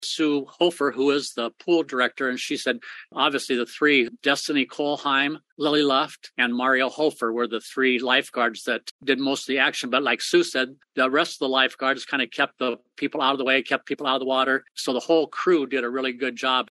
That’s Mayor Dean Andrews, who announced during Wednesday night’s City Council meeting, that a plaque will be coming to the pool to honor the life-saving group effort.